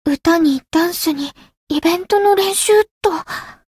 灵魂潮汐-梦咲音月-闲聊2.ogg